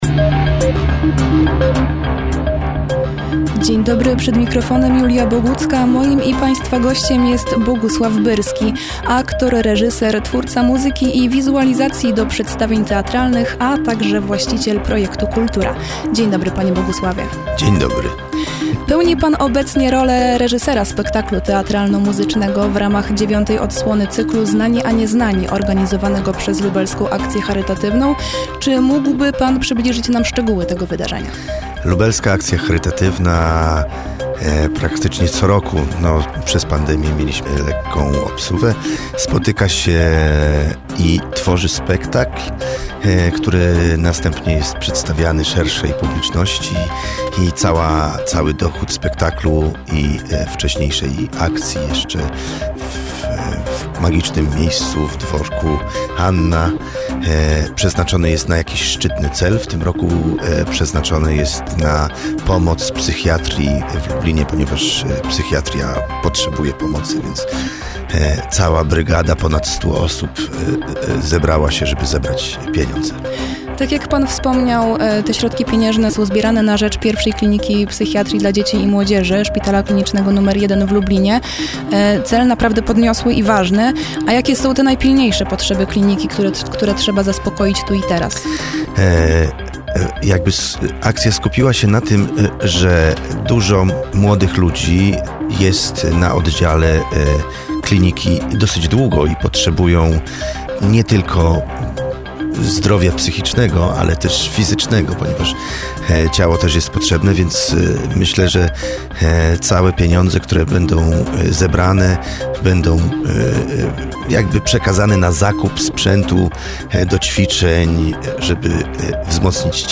Opublikowano w Aktualności, Kultura, Poranna Rozmowa Radia Centrum, Wydarzenia